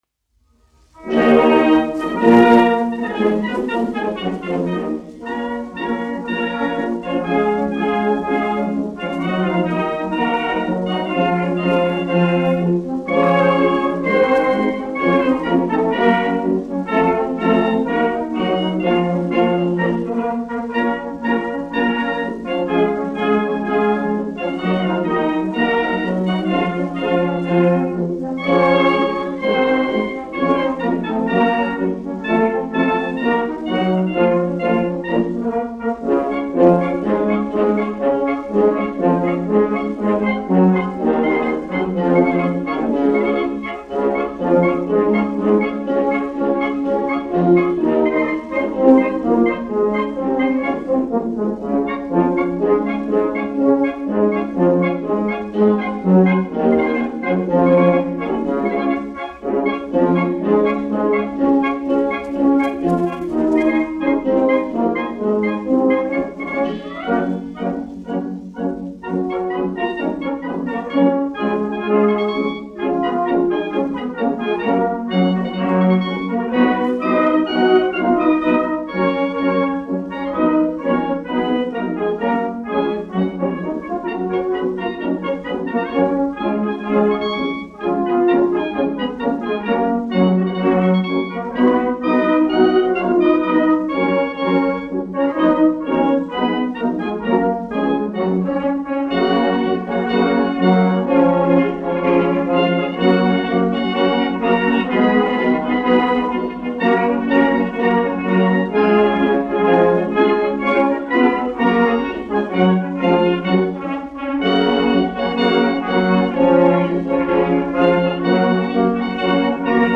Latvijas 4. Valmieras kājnieku pulka orķestris, izpildītājs
1 skpl. : analogs, 78 apgr/min, mono ; 25 cm
Marši
Pūtēju orķestra mūzika
Skaņuplate